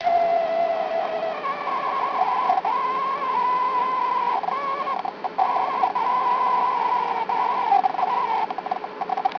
ヘッドから共振音も出ていたので、これはボイスメモで
afmnoise.wav